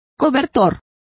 Complete with pronunciation of the translation of coverlets.